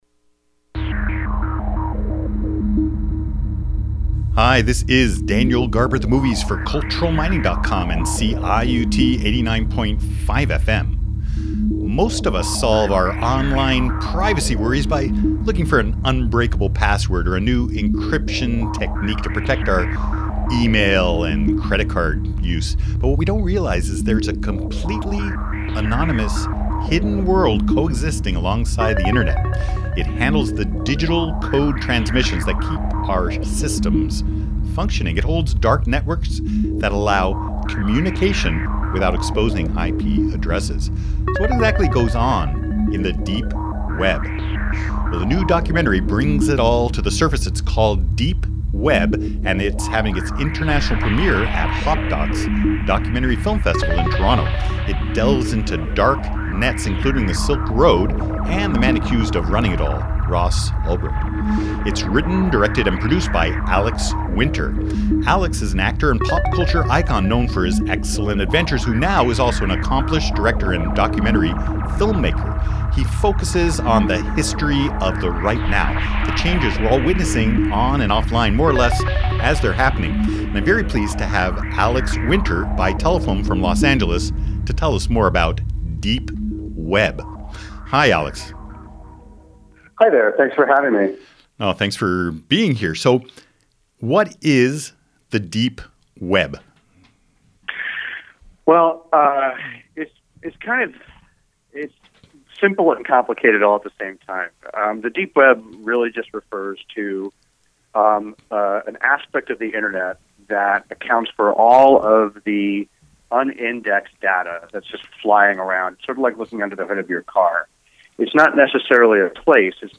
I speak to Alex Winter by telephone in Los Angeles.